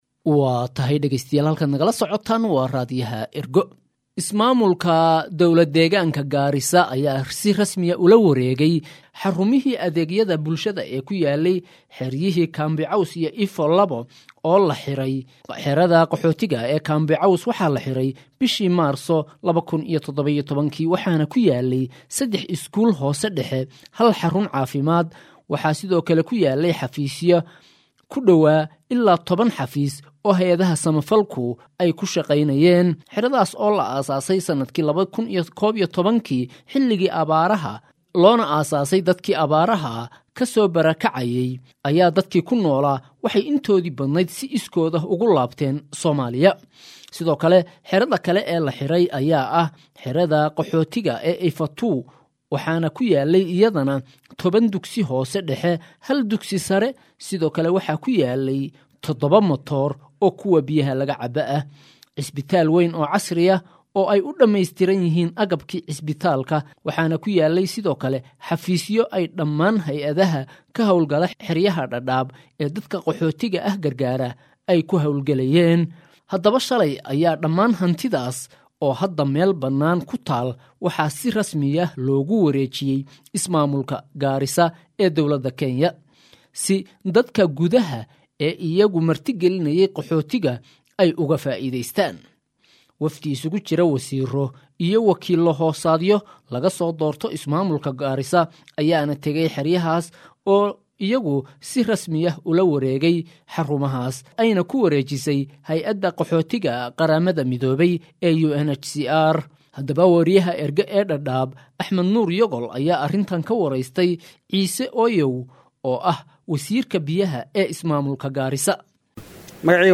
Waxaana arrinaan Ergo uga waramay Ciise Oyow oo ah wasiirka biyaha ee ismaamulka Garisa.
wareysi-wasiirka-biyaha-ismaamulka-Garisaa-.mp3